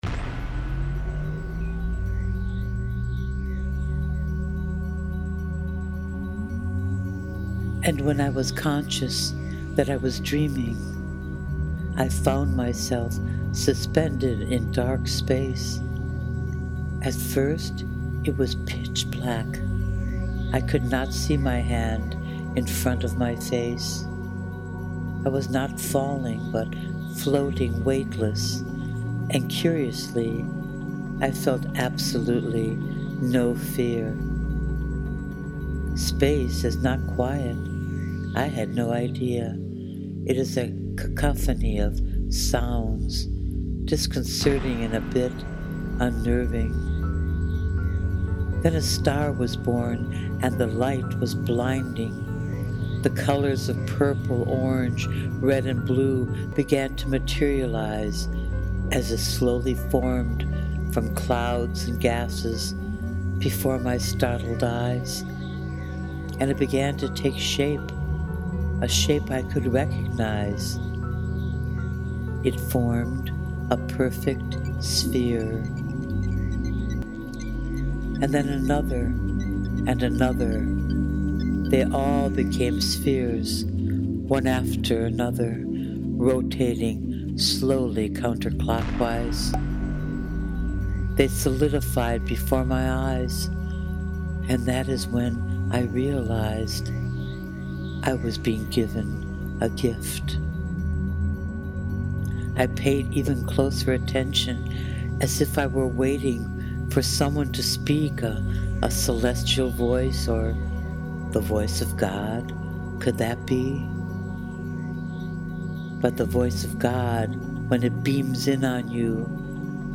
You’ve described this gift so beautifully and evocatively; and then given it such a soulful voice.